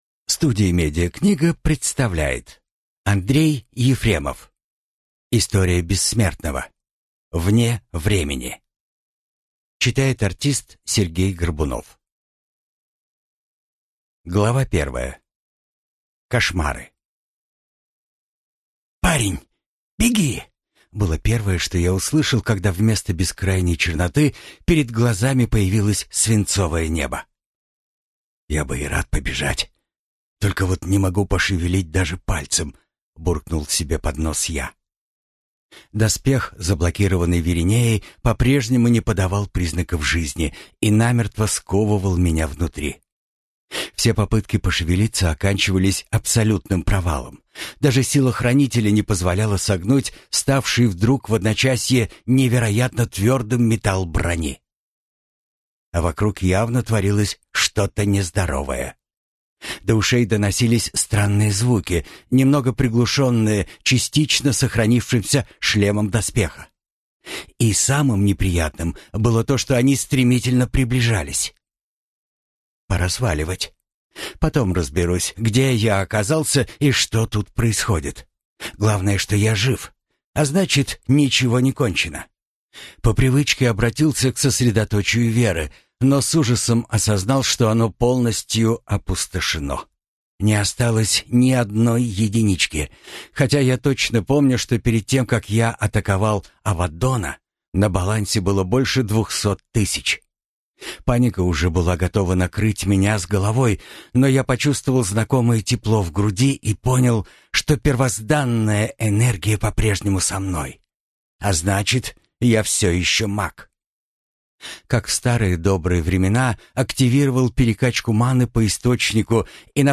Аудиокнига История Бессмертного-9. Вне времени | Библиотека аудиокниг